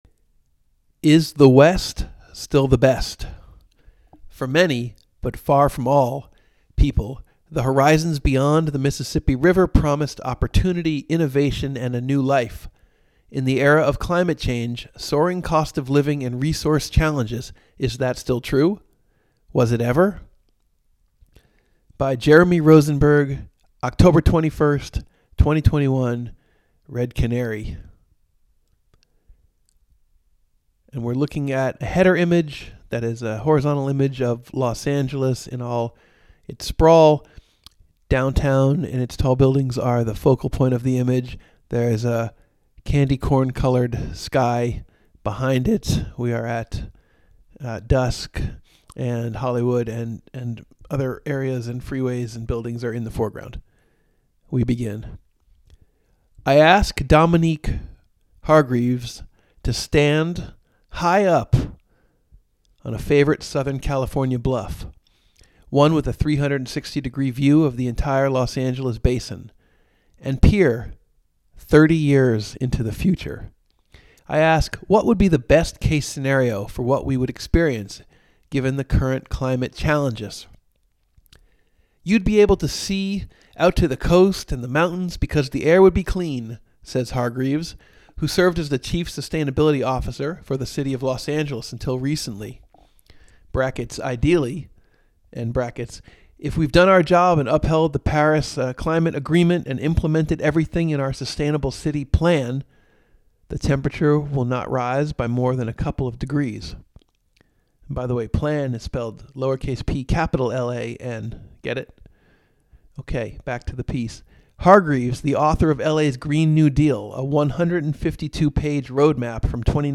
Listen to an audio version of the story, read by the author.